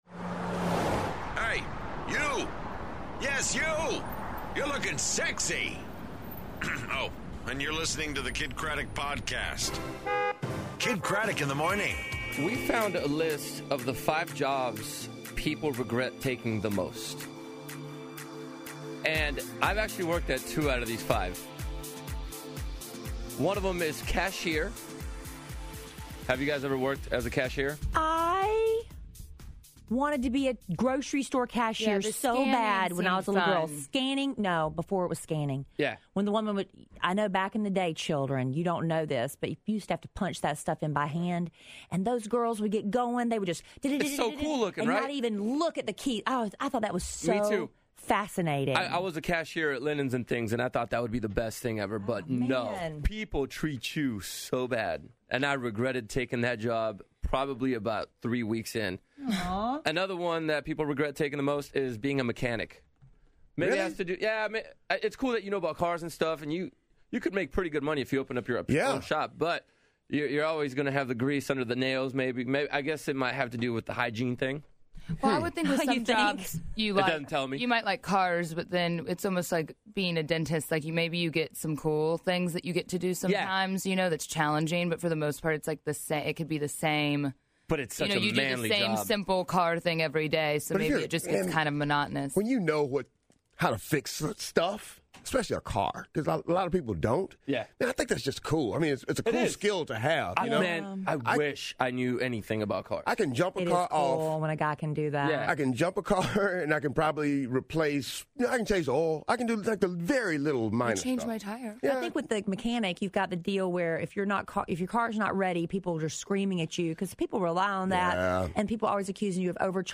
Valentine's Day Teacher Test, Sean Lowe and Catherine Giudici In Studio, And Kevin Hart On The Phone